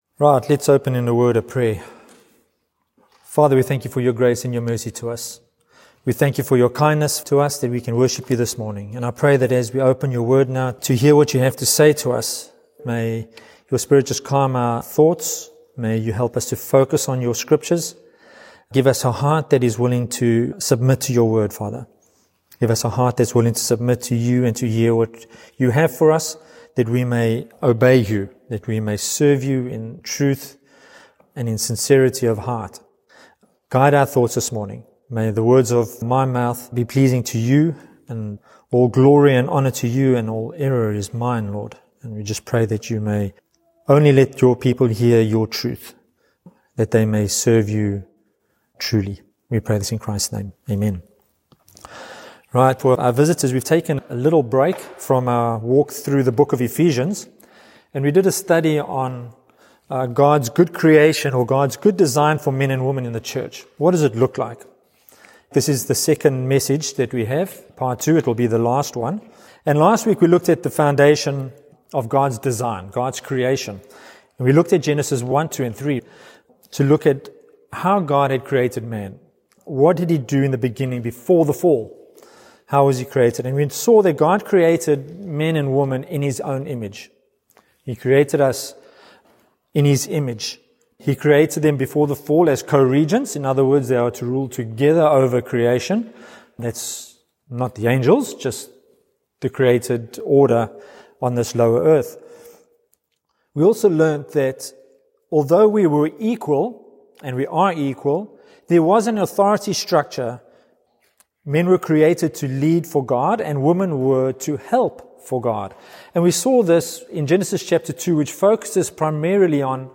In this sermon, we consider what the Bible teaches about men and women in the life of the church.